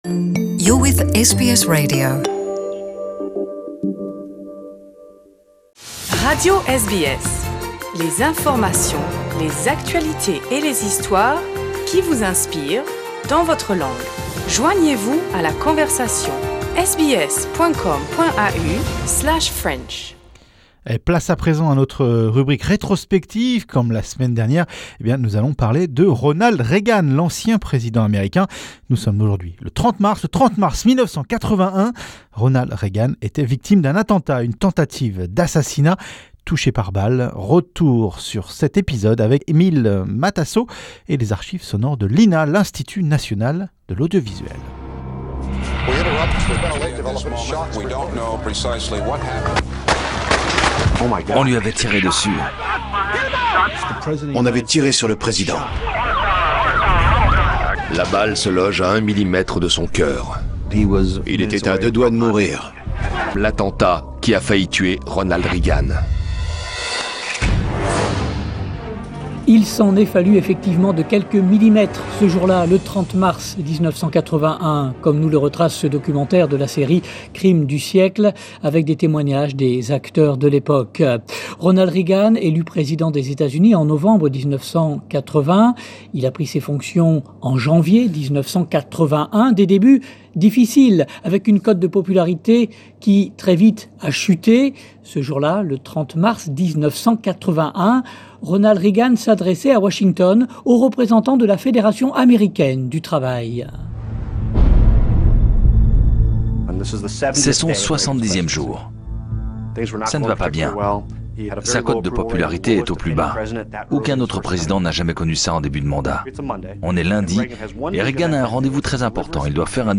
Retour sur cet épisode avec les archives sonores de l'INA..l'Institut National de l'Audiovoisuel